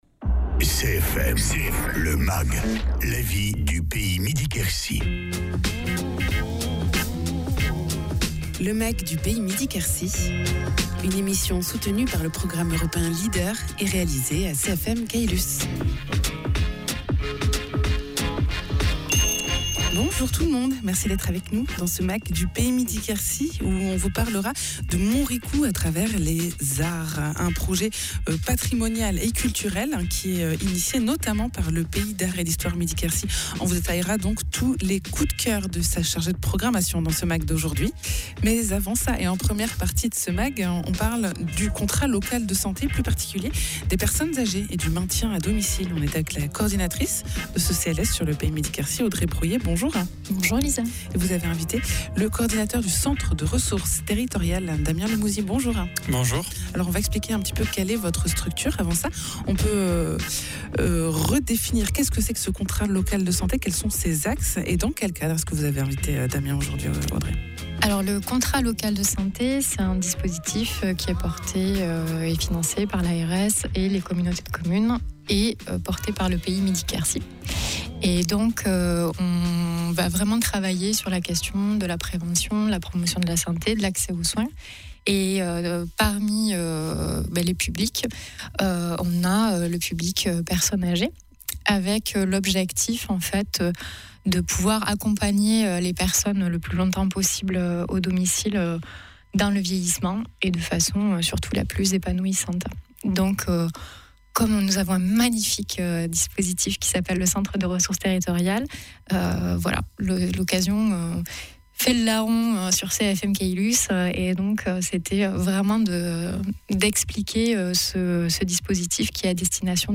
Michel Janin, élu à Montricoux